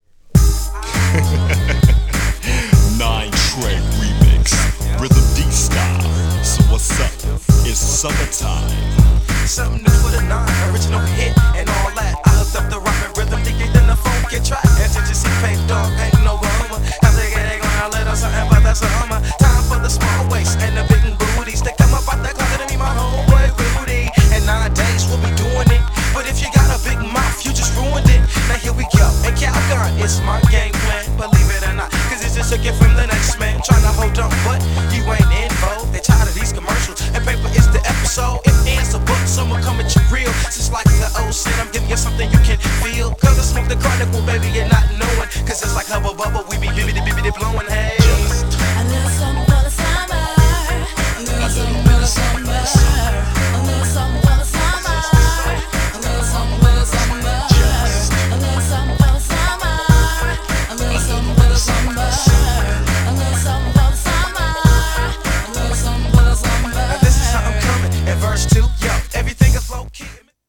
REMIXはブリブリのヘビーなベースにノリの良いコーラスも乗るサマーアンセムなG FUNK!!
LP VERSIONはFUNKY!!
GENRE Hip Hop
BPM 101〜105BPM